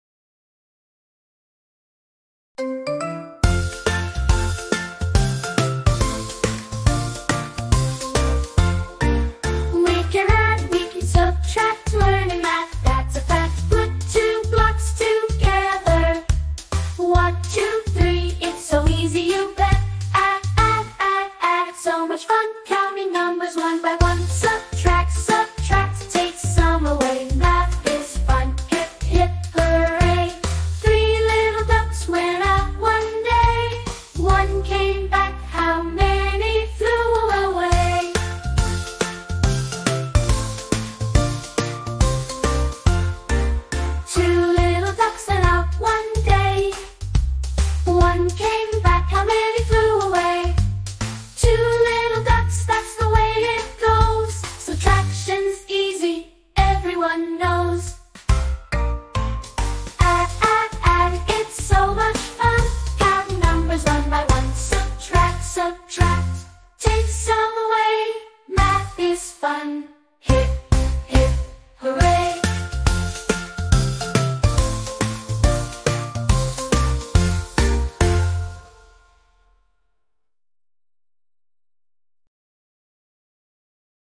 Fun and Exciting Kids Song